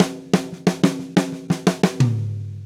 Drumset Fill 18.wav